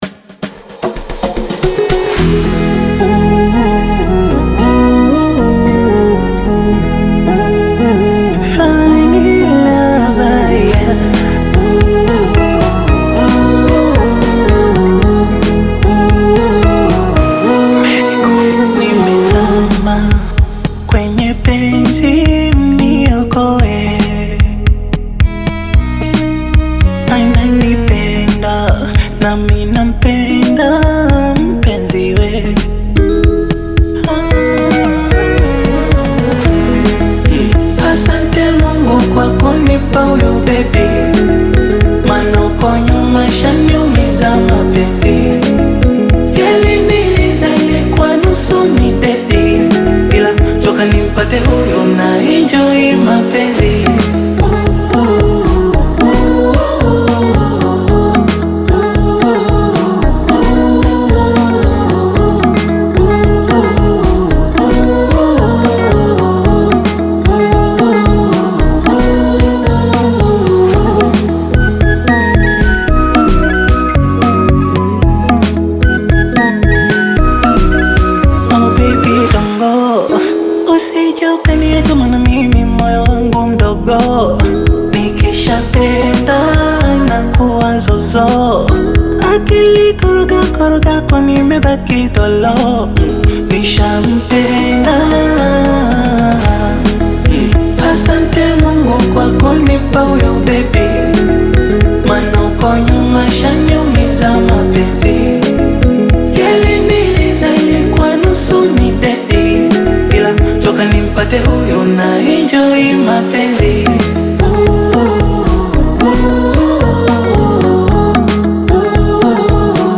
blends soft melodies with modern production